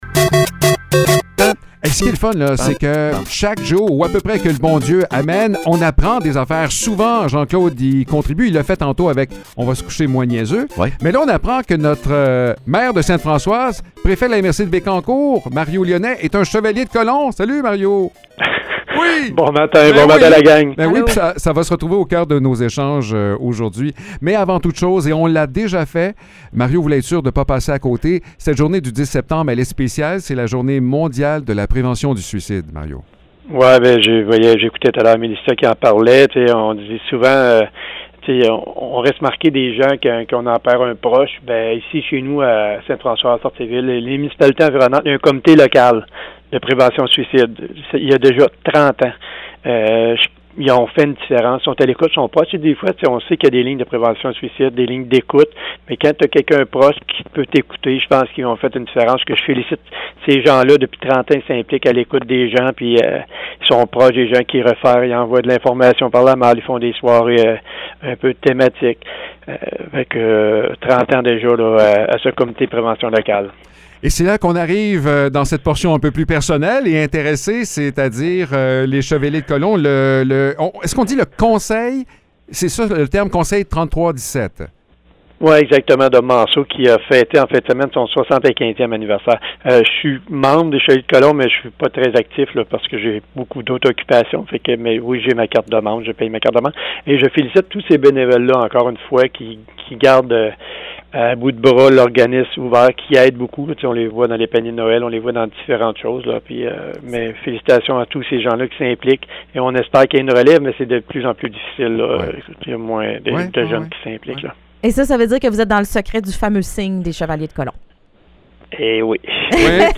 Mario Lyonnais, maire du village de Sainte-Françoise et préfet de la MRC de Bécancour, reçoit des souhaits d’anniversaires chantés par l’équipe du matin !